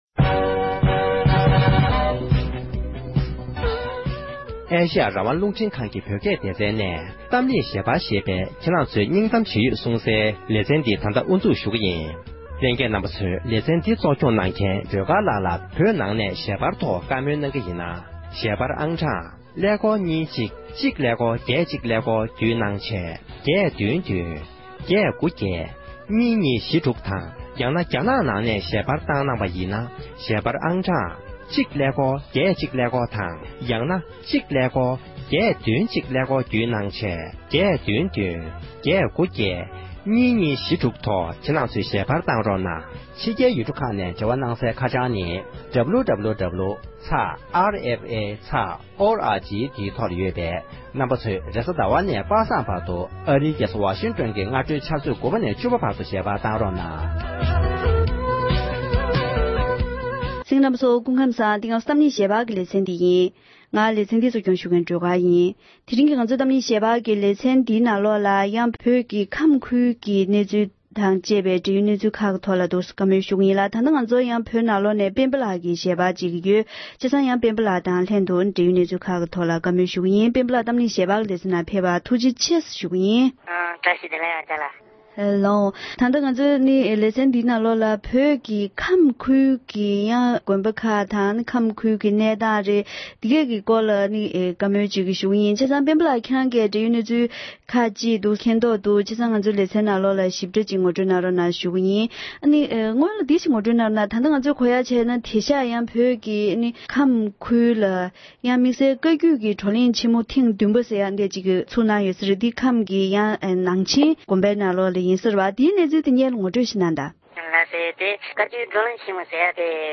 བོད་ནང་ཡོད་མཁན་གྱི་བོད་མི་ཞིག་དང་ལྷན་དུ་བཀའ་མོལ་ཞུས་པ་ཞིག་ལ་གསན་རོགས་ཞུ༎